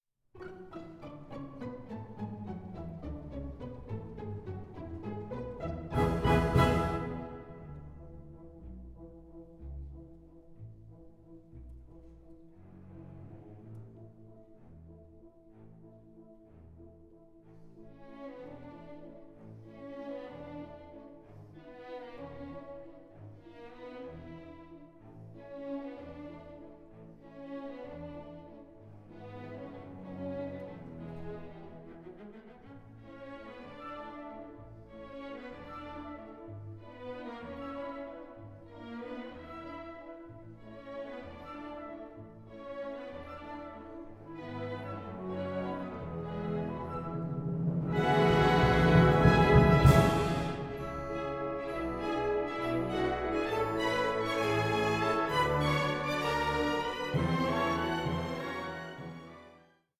ballet in four acts